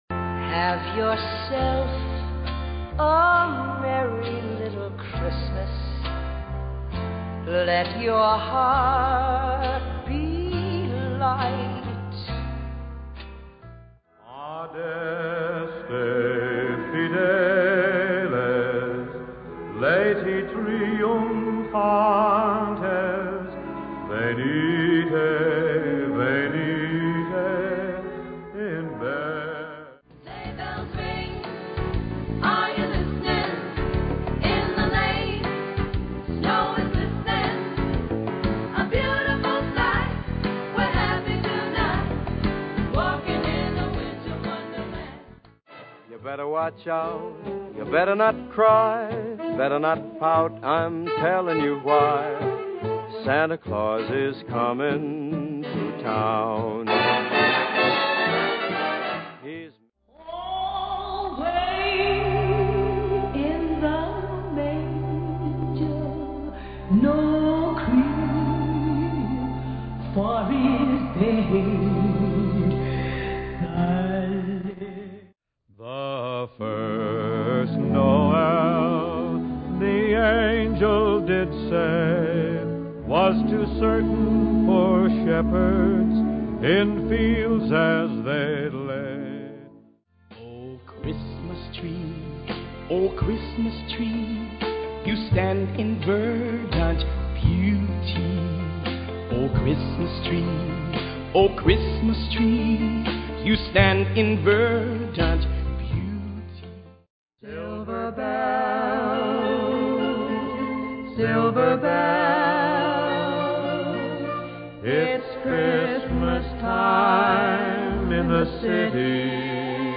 A star-studded Christmas compilation
14 Christmas favorites!